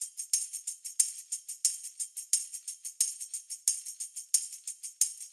Index of /musicradar/sampled-funk-soul-samples/90bpm/Beats
SSF_TambProc1_90-01.wav